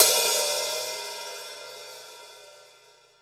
Index of /90_sSampleCDs/Total_Drum&Bass/Drums/HiHats
2a_closed_.wav